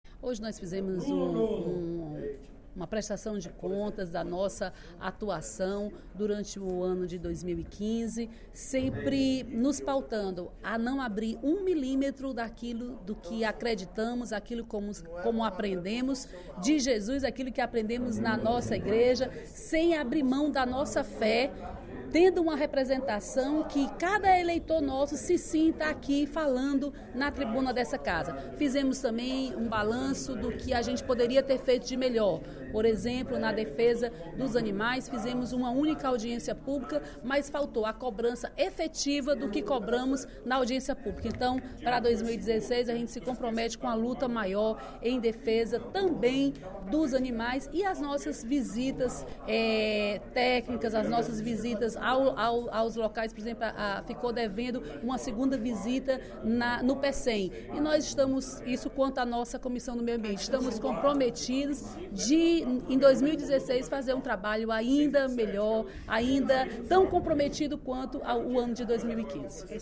A deputada Dra. Silvana (PMDB) prestou contas, nesta sexta-feira (18/12), durante o primeiro expediente da sessão plenária, do seu mandato durante o ano de 2015. A parlamentar agradeceu ao povo a confiança depositada nela para representá-lo e destacou sua alegria e compromisso com a sociedade cearense.